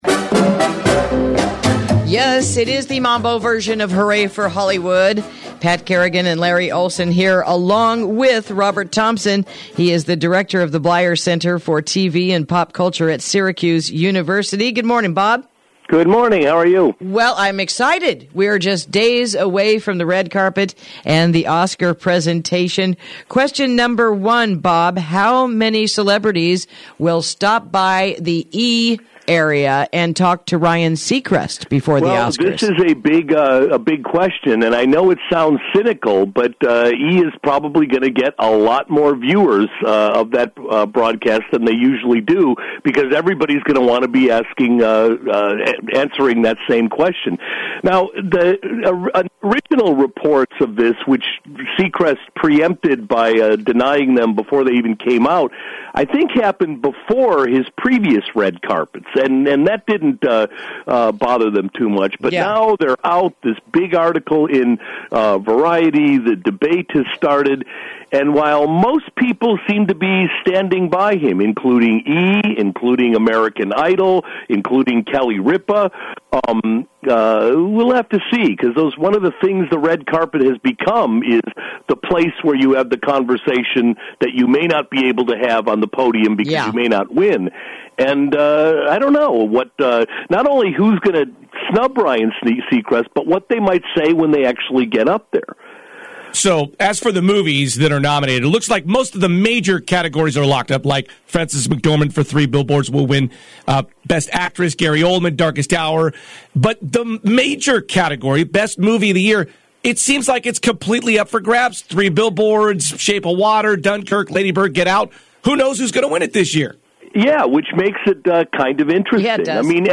Interview: 2018 Oscars Preview for This Sunday Night